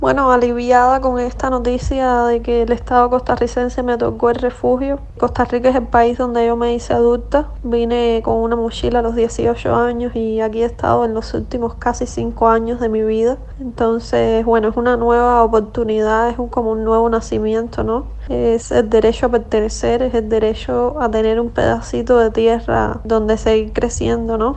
"Costa Rica es el país donde yo me hice adulta. Vine con una mochila a los 18 años y aquí he estado en los últimos casi 5 años de mi vida. Entonces bueno es una nueva oportunidad. Es como un nuevo nacimiento; es el derecho a pertenecer… es el derecho a tener un pedacito de tierra donde seguir creciendo", declaró la periodista cubana a Radio Martí.